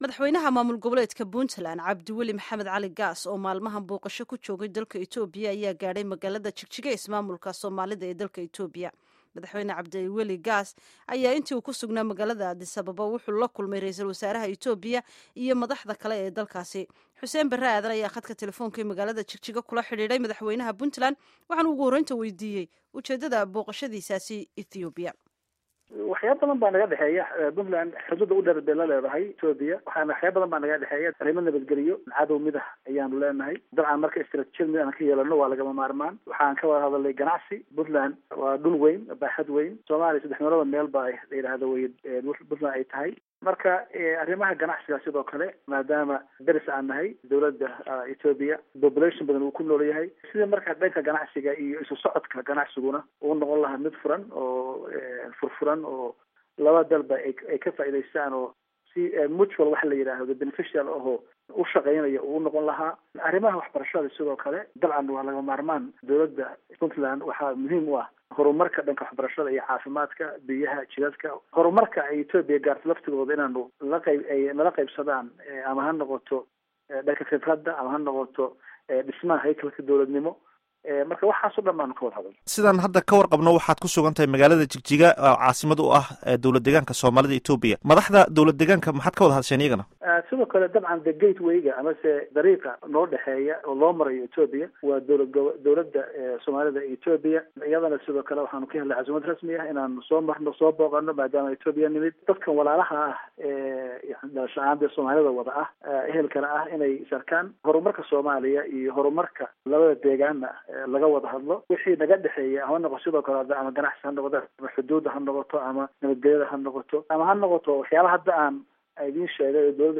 Waraysi: Madaxweynaha Puntland